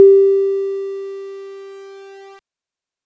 This will produce an almost similar signal, spectrum and sound wise.
FMDrive MD1 mode recording
FMDrive_LADDER.wav